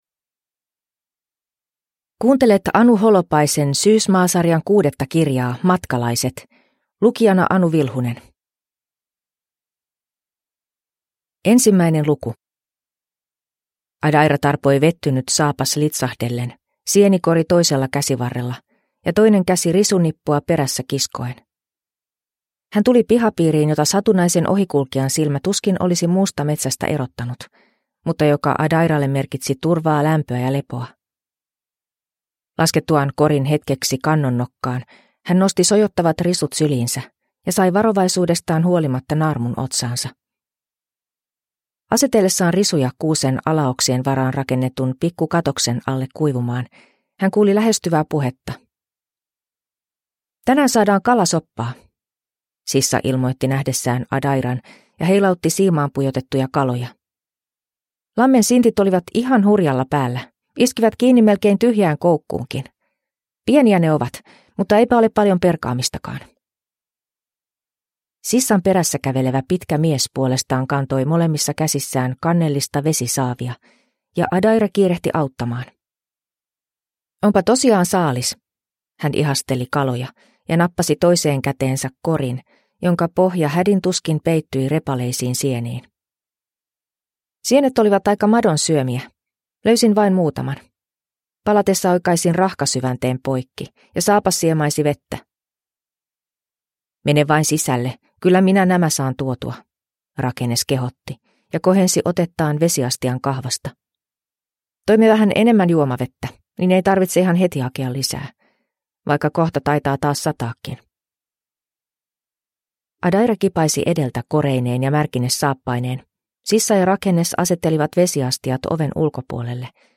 Matkalaiset – Ljudbok